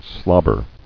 [slob·ber]